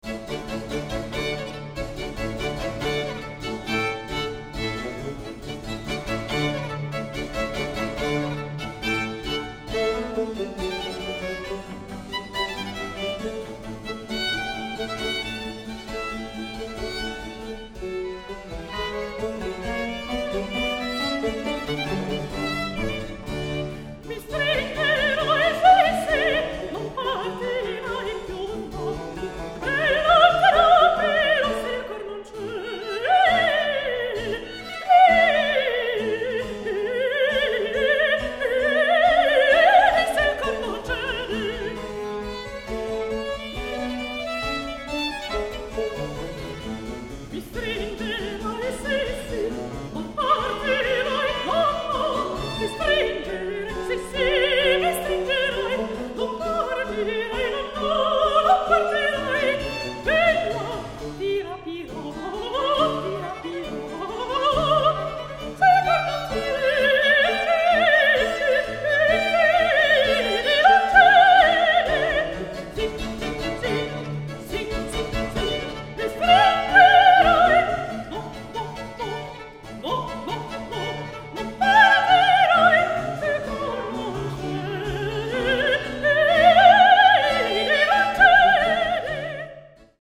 mezzosoprano